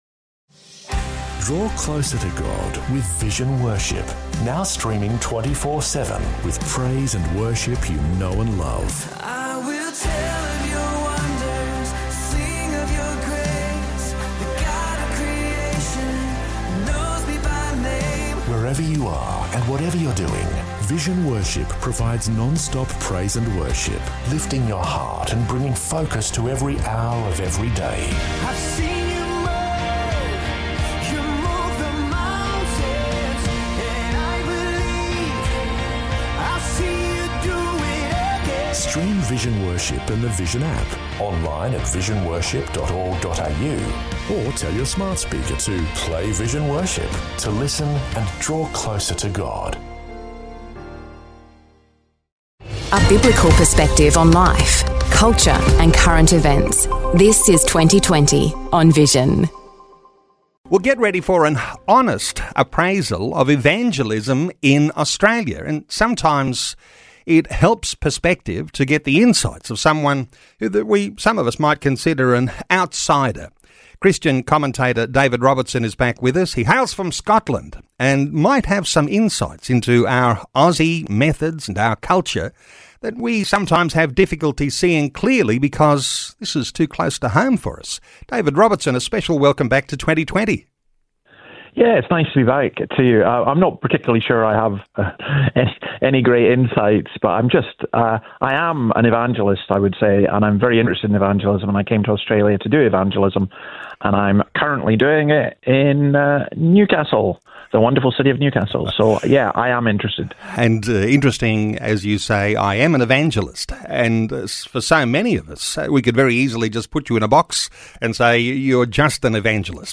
You can hear the whole interview below: We discuss what evangelism is; how it is done in Australia today; should evangelists lead a church; Billy Graham etc….and the Nexus conference….